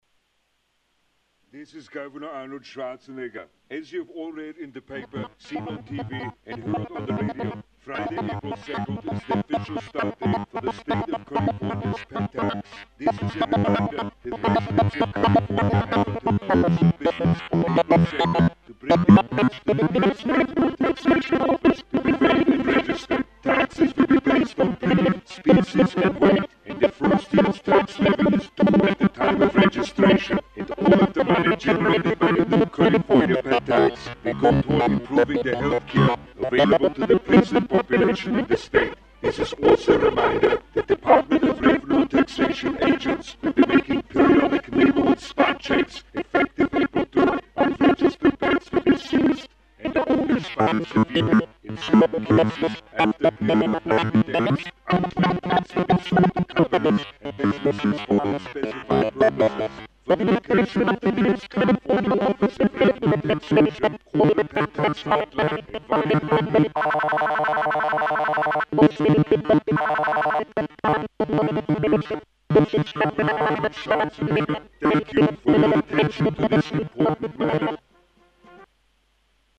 Below you can find three mp3 files which demonstrate some audio effects the HT8950 can produce.
You can hear how I change the frequency and vibrato. I also play with the mixing between normal audio and modulated audio.
Robot sound with vibrato :